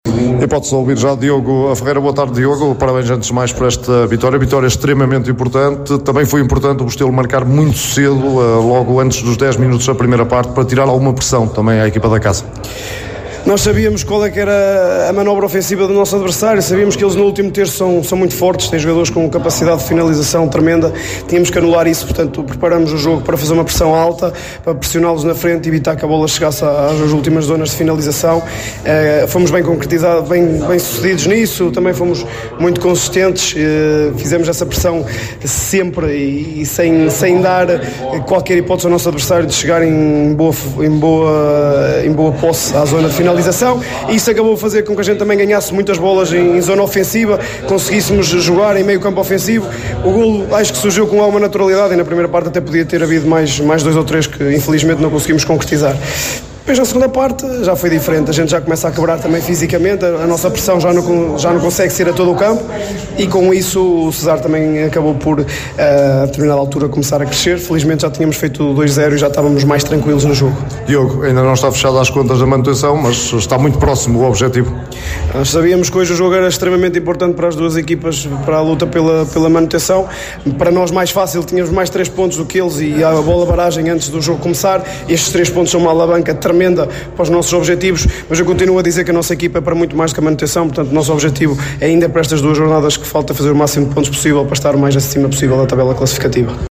Aos microfones da Sintonia